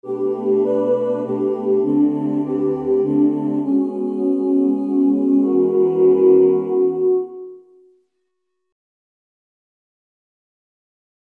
Key written in: E Major